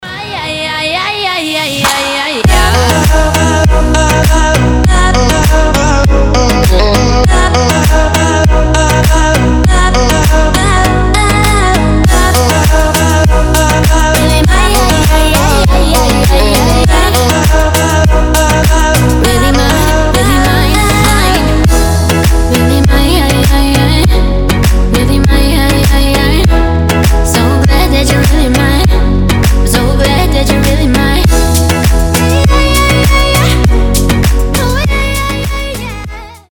• Качество: 320, Stereo
женский вокал
заводные
dance
Electronic
EDM
Midtempo
tropical house